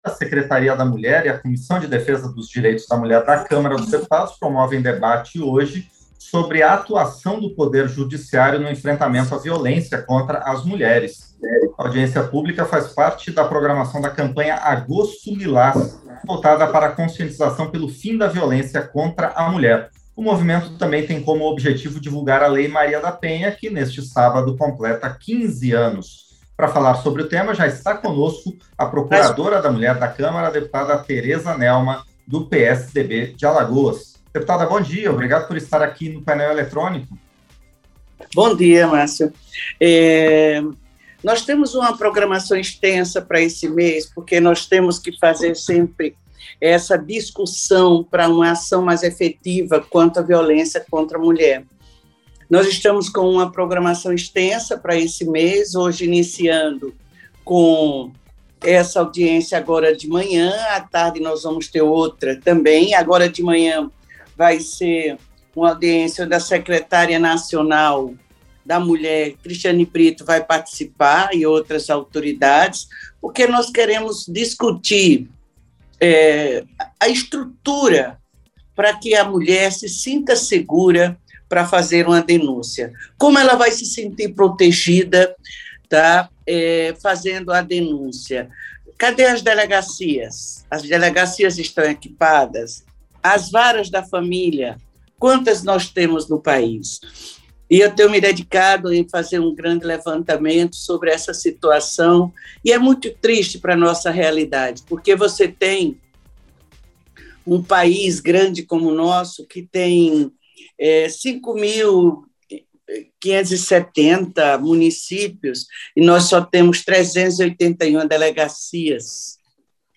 Entrevista - Dep. Tereza Nelma (PSDB/AL)